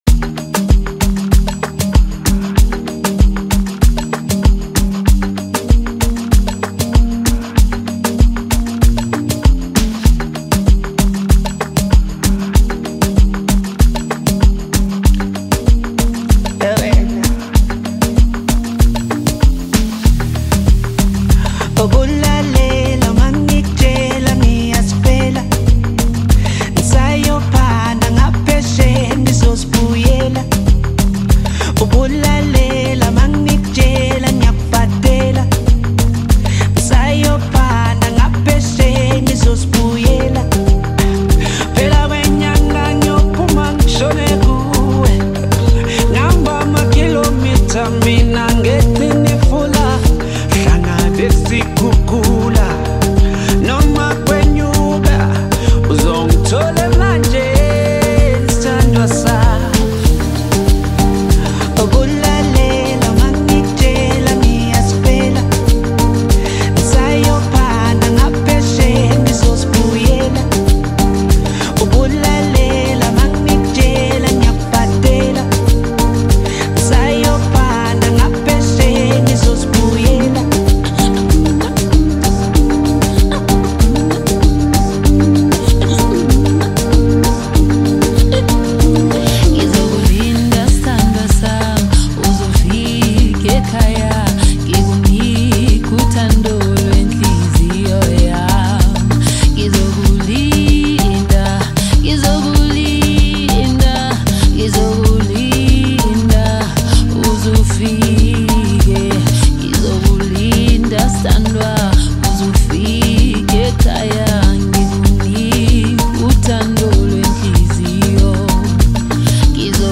dance song